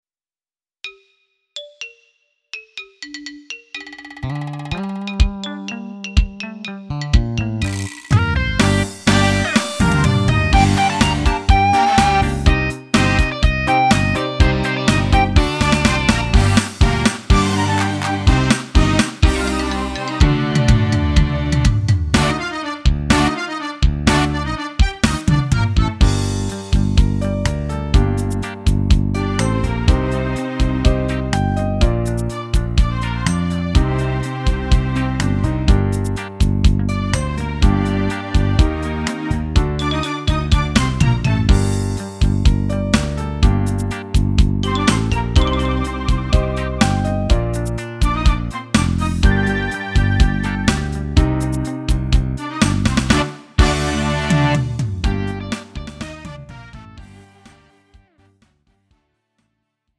음정 -1키 3:37
장르 가요 구분 Lite MR